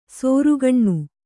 ♪ sōrugaṇṇu